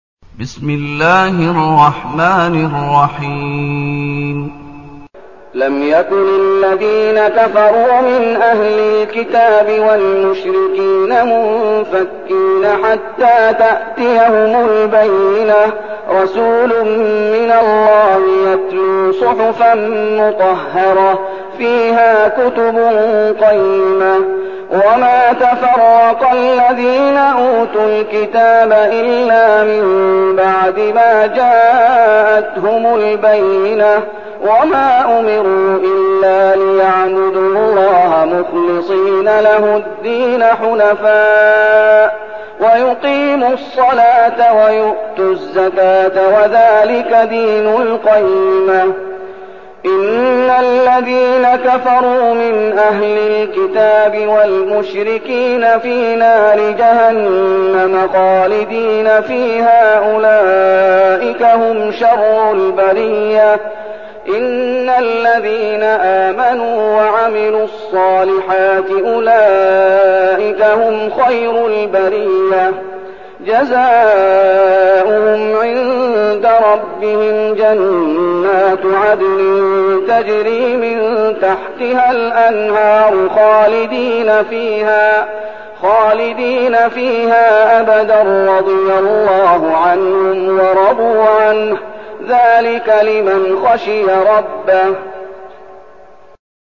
المكان: المسجد النبوي الشيخ: فضيلة الشيخ محمد أيوب فضيلة الشيخ محمد أيوب البينة